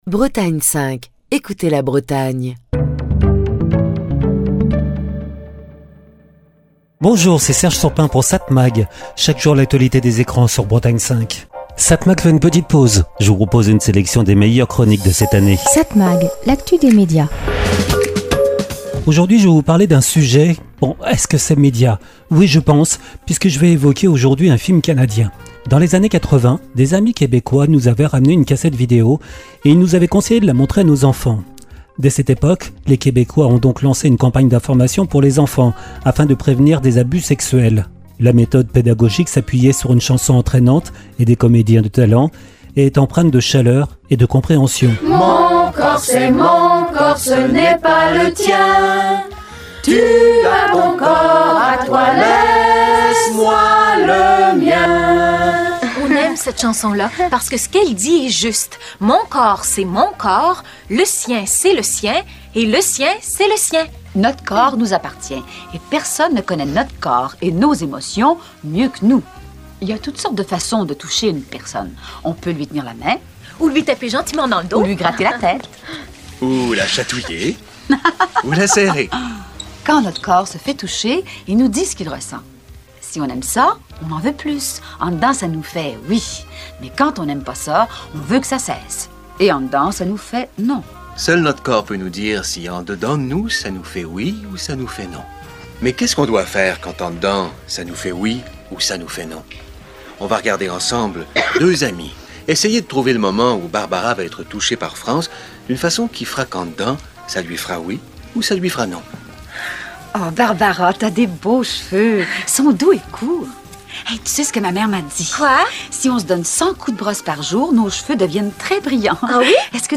Chronique du 24 juin 2025.